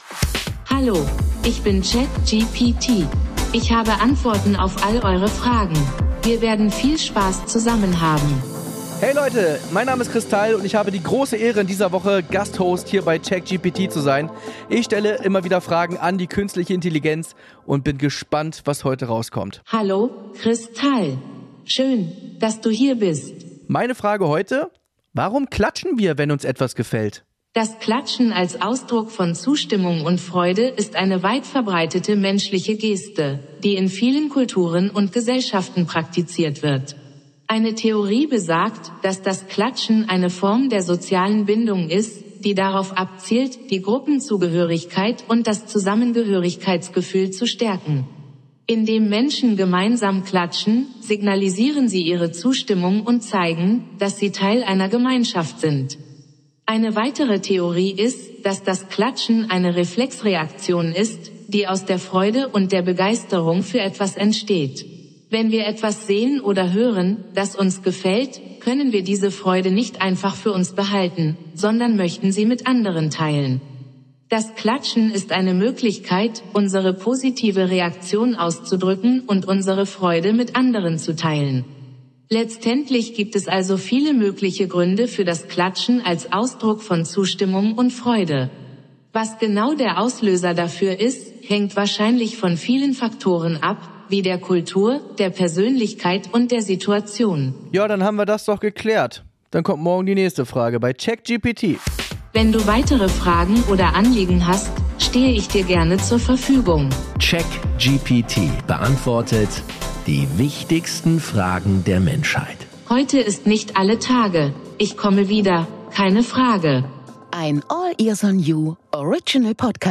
Chris Tall & KI
erfolgreichster Comedian übernimmt für eine Woche die Moderation